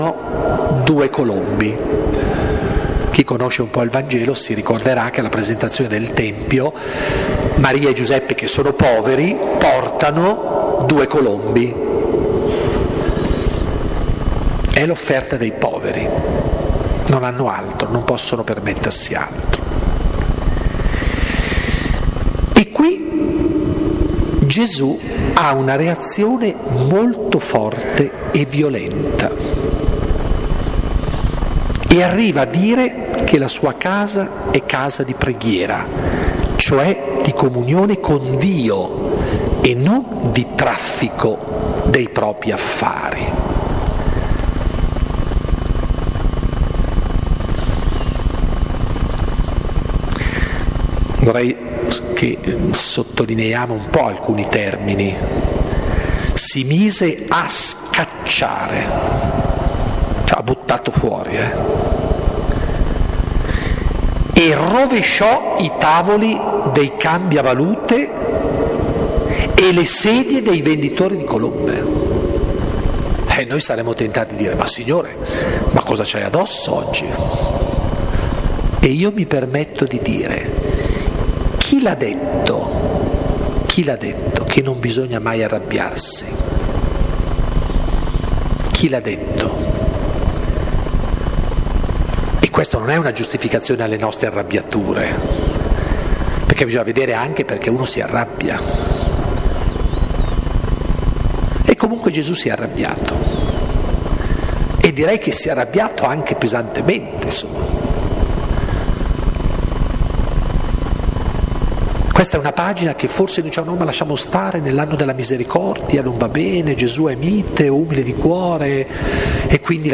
4° Incontro - Lectio - Lo sguardo senza parole - Comunità Pastorale "San Paolo"